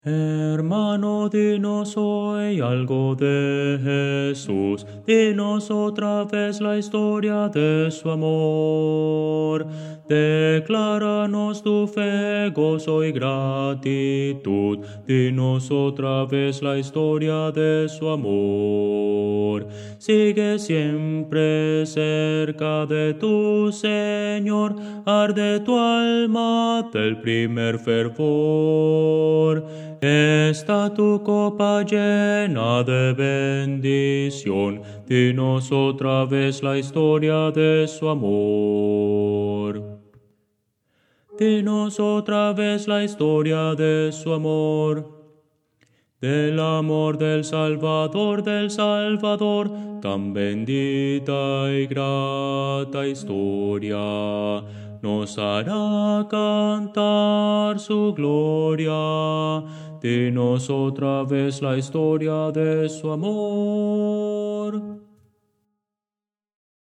Voces para coro
Bajo